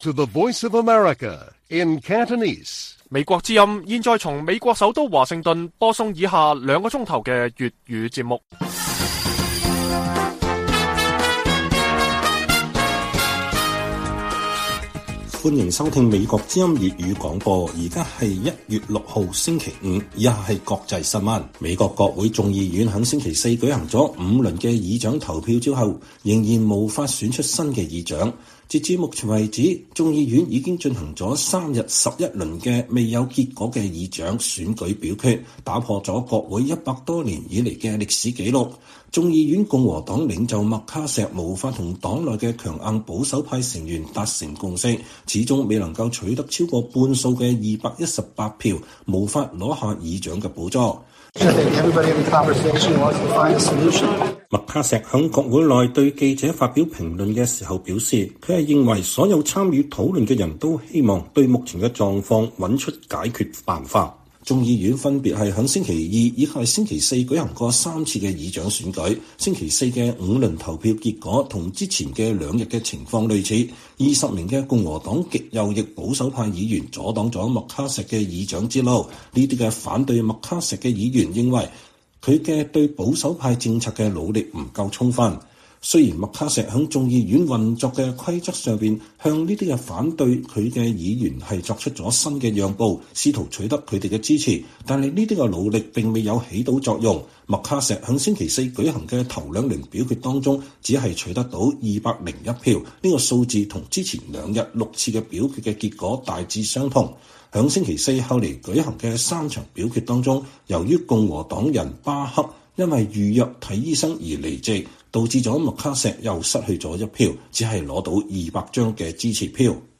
粵語新聞 晚上9-10點: 美國眾院議長選舉三天舉行11輪表決共和黨領袖麥卡錫仍然遇阻